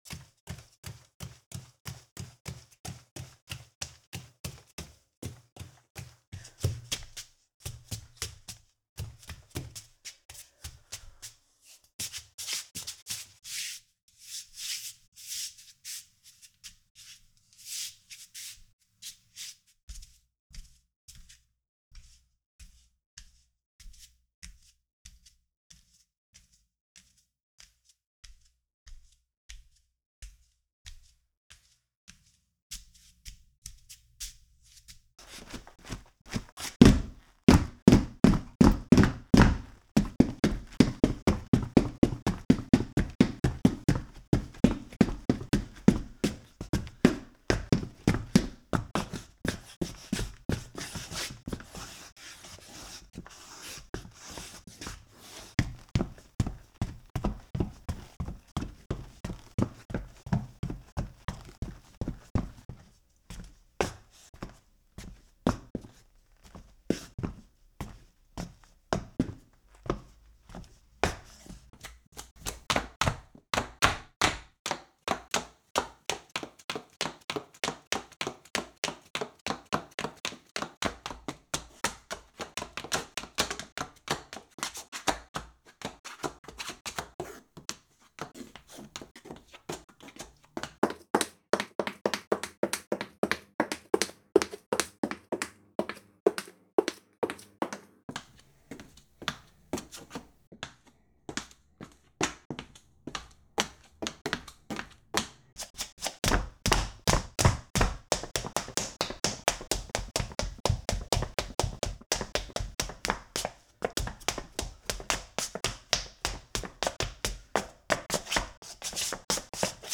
Footsteps Ceramic Tile